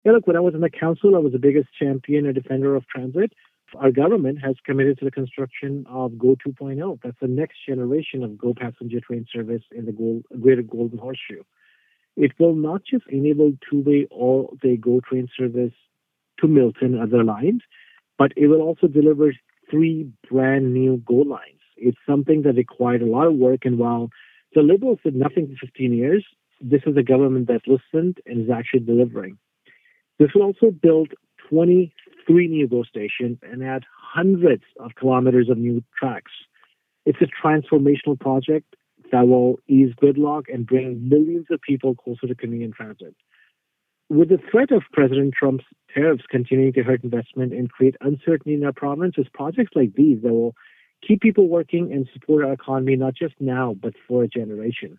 He was available by phone for this interview.